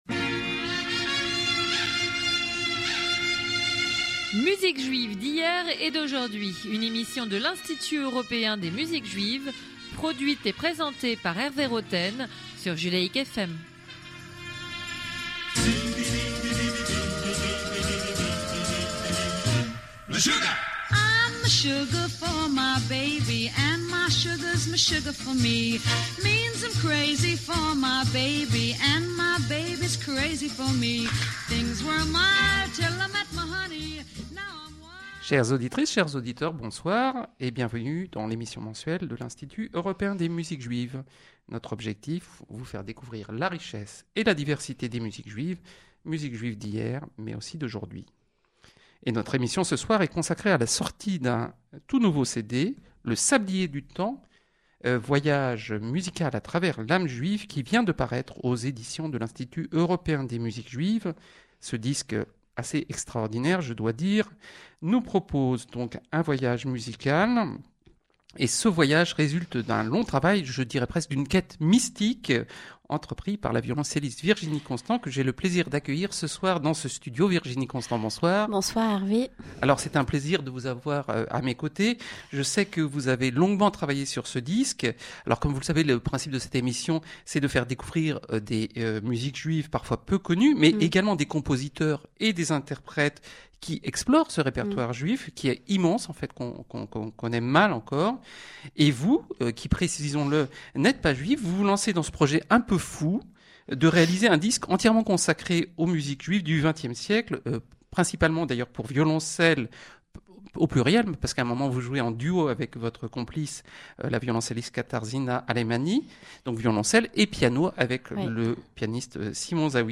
Radio program in French